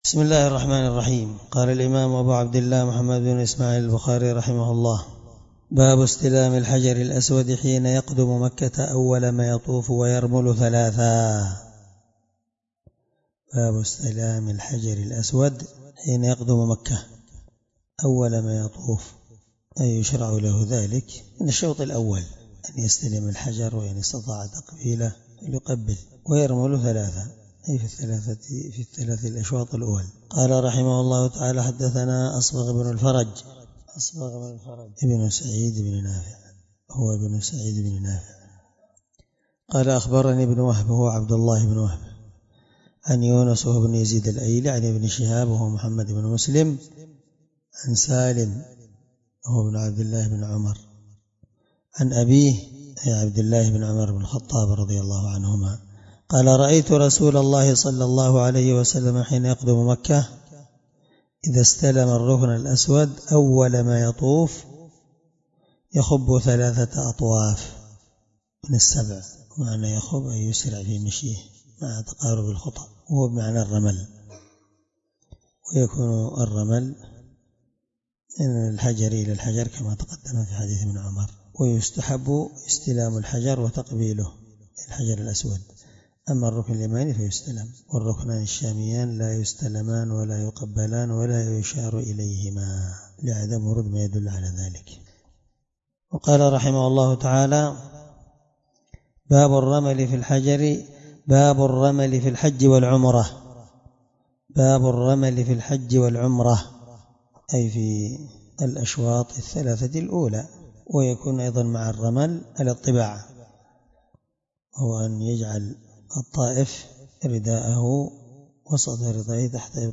الدرس40 من شرح كتاب الحج حديث رقم(1603-1606 )من صحيح البخاري